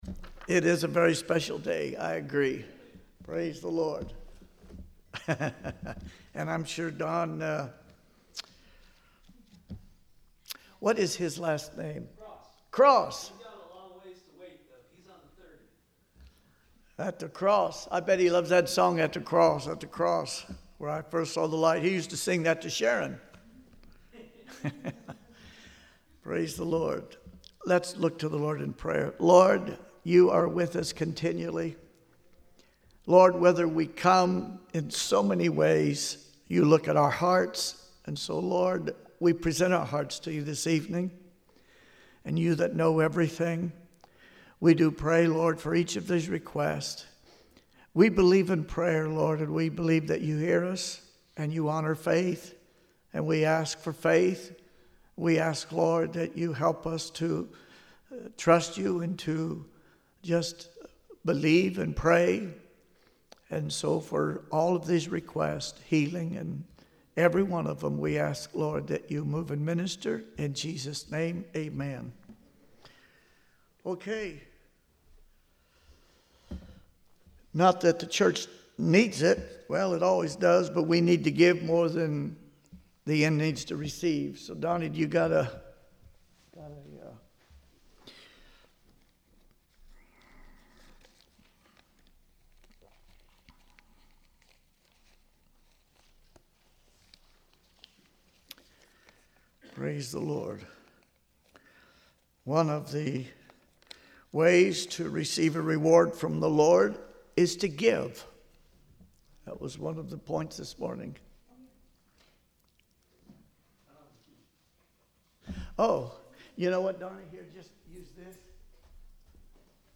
(Sermon begins at 3:00 minutes in)